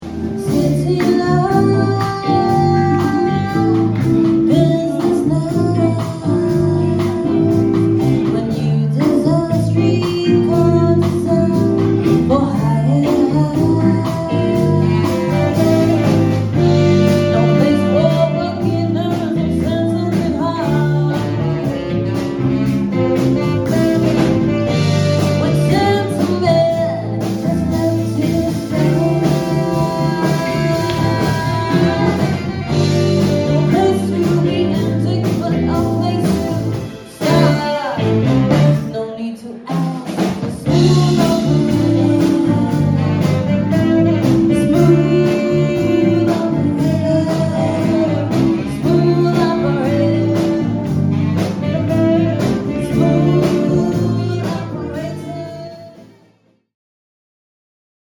funk, soul, pop and rock